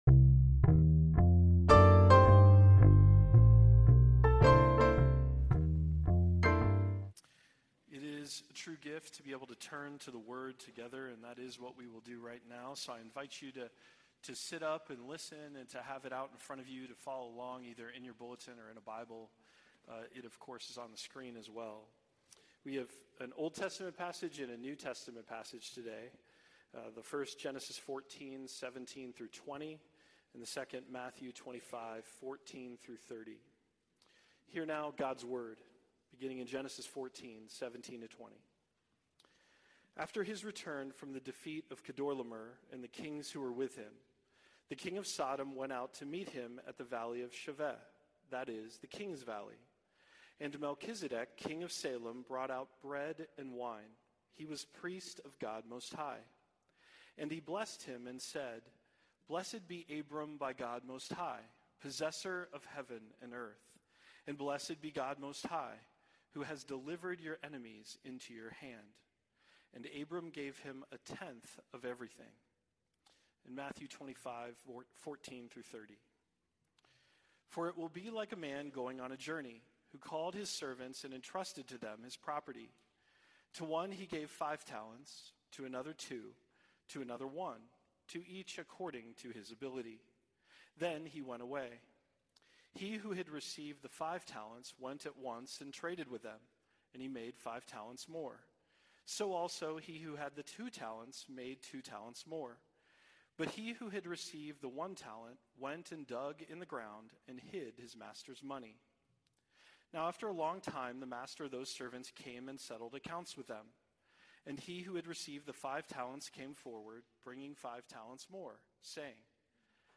Five to Go Passage: Genesis 14:17-20, Matthew 25:14-30 Service Type: Sunday Worship « Five Down